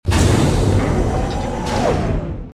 dooropen.ogg